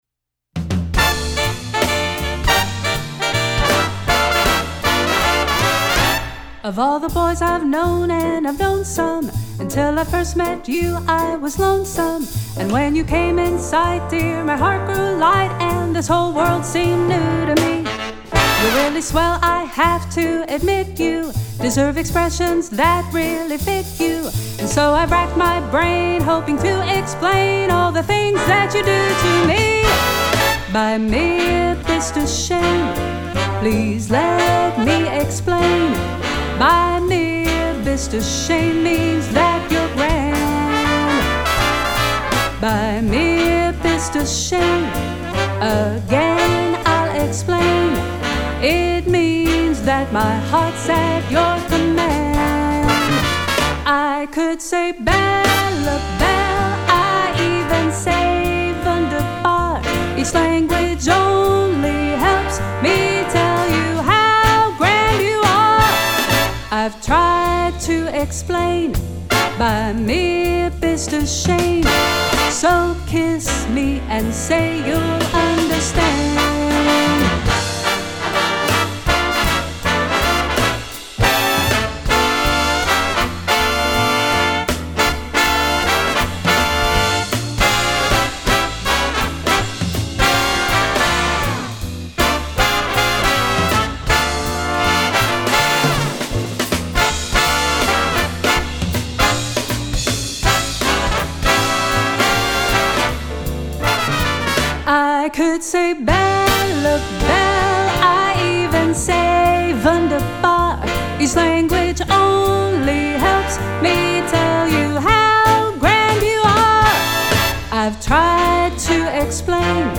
Voicing: Big Band with Vocal